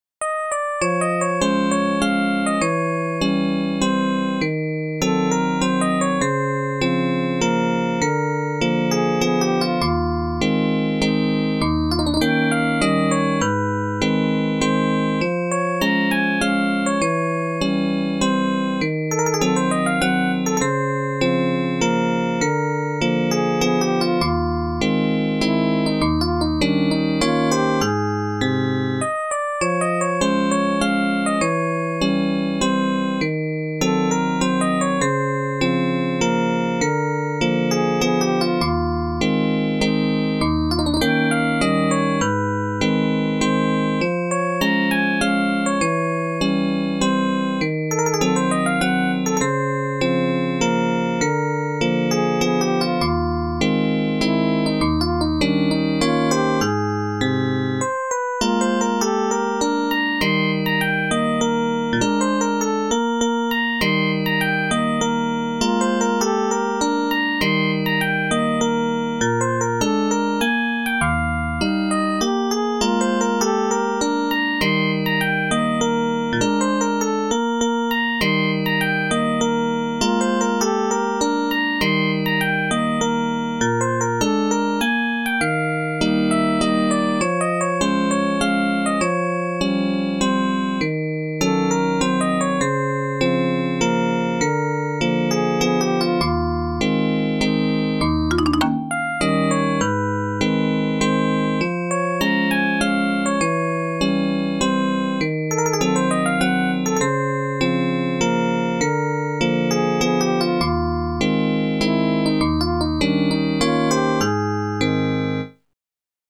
Waltz as synthesized using Midi Chorus timbre.WAV
waltz-as-synthesized-using-midi-chorus-timbre.wav